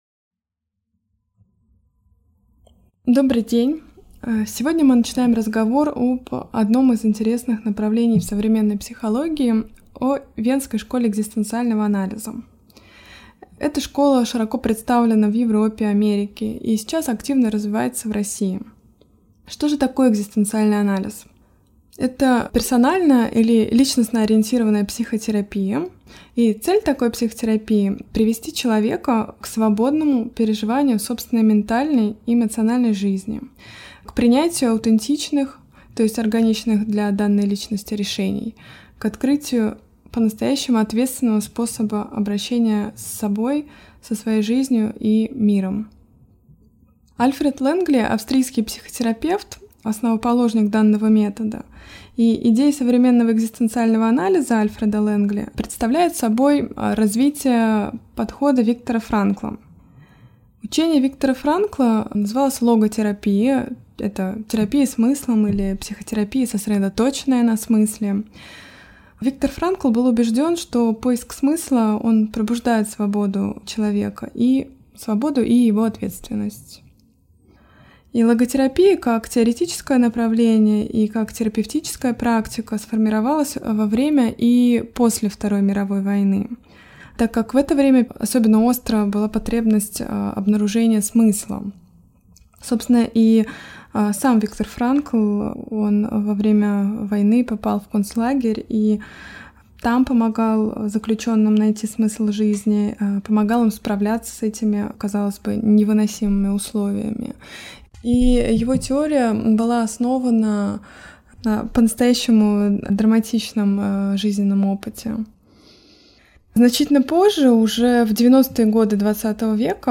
Аудиокнига Теория фундаментальных мотиваций | Библиотека аудиокниг